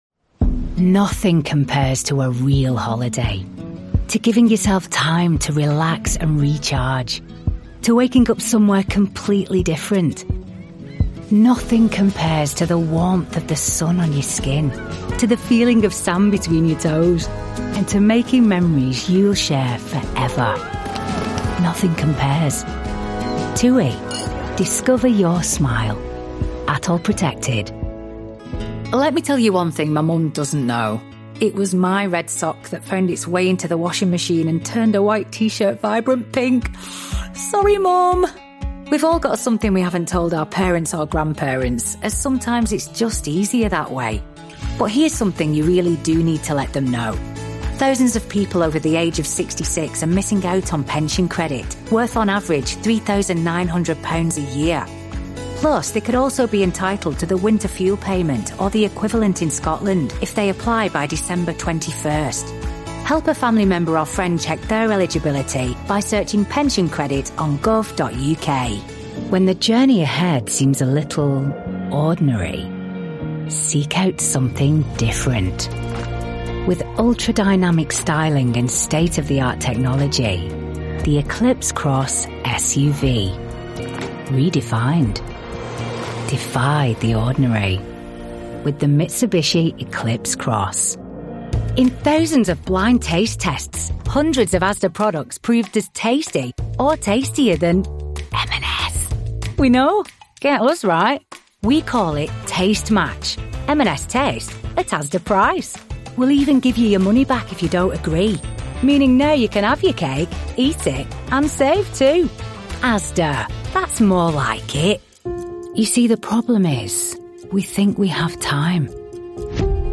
Rapide, fiable et naturellement conversationnelle, elle offre une voix off professionnelle qui captive et captive.
Démo commerciale
* Cabine de son spécialement conçue, isolée et traitée acoustiquement
* Micro et protection anti-pop Rode NT1-A